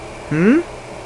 Hmmm Sound Effect
Download a high-quality hmmm sound effect.
hmmm-1.mp3